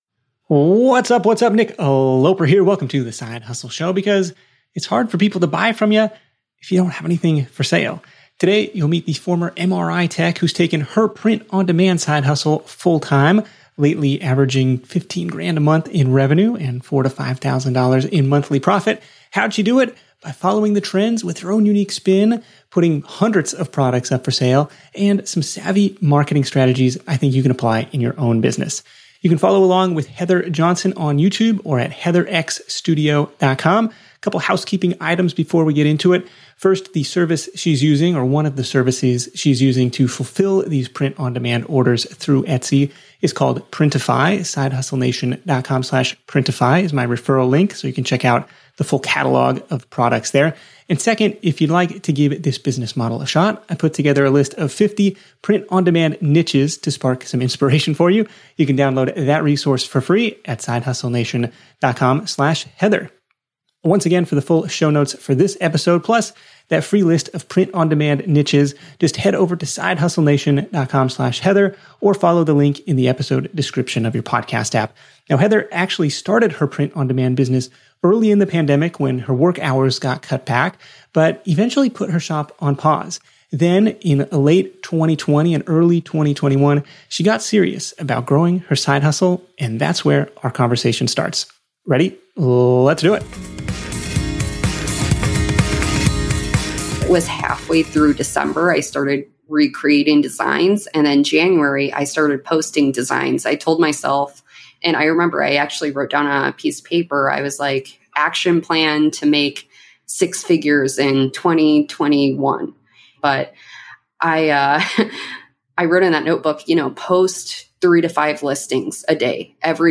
Tune in to The Side Hustle Show interview to hear